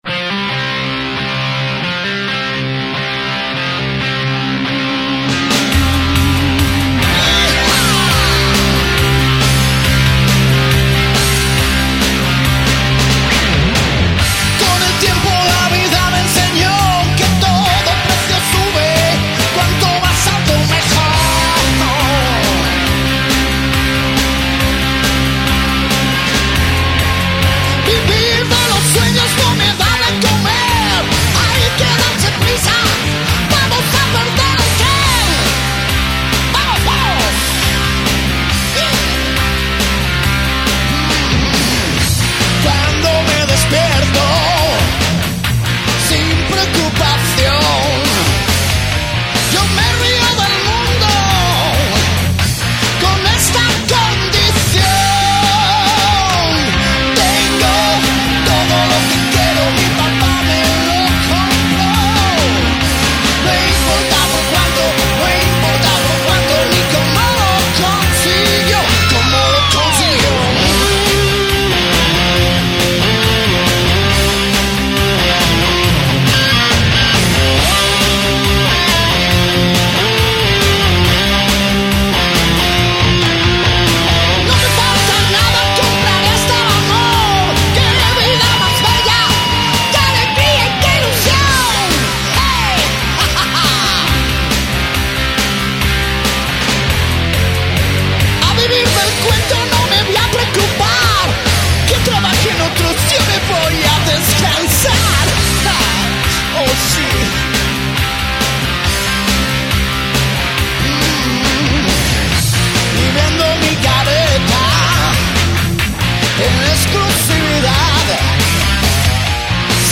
prestigioso guitarrista